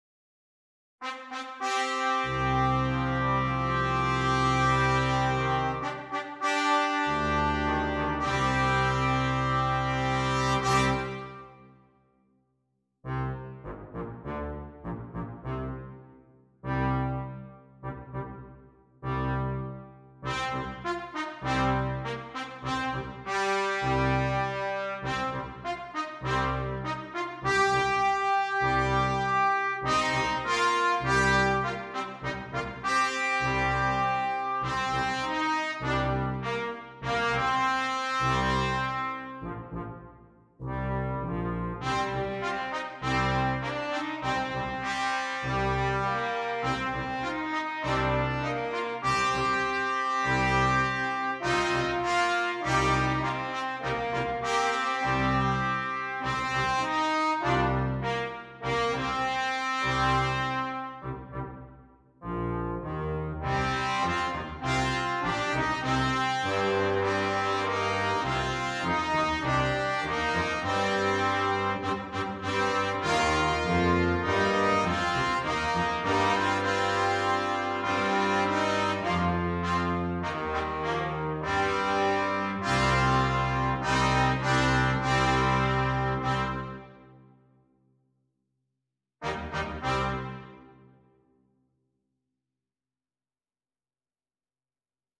Brass Quintet
powerful themes and strong rhythmic writing